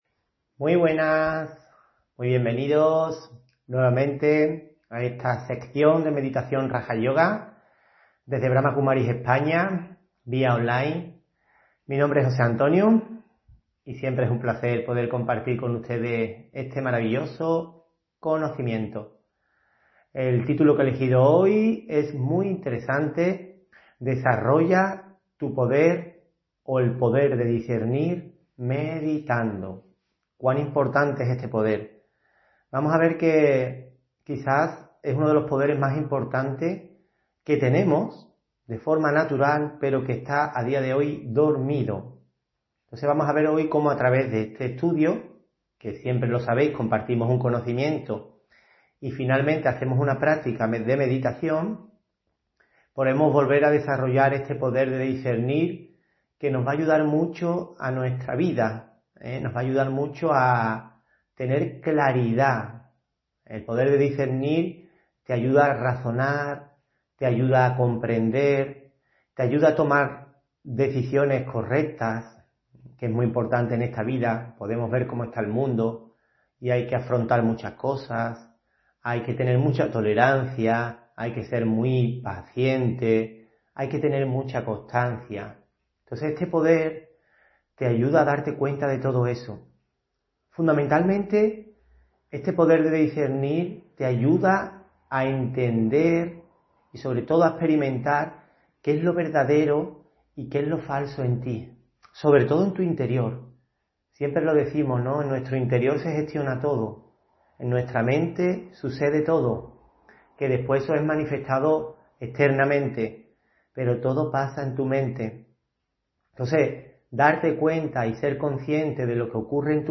Audio conferencias
Meditación y conferencia: Desarrolla el poder de discernir meditando (13 Diciembre 2023)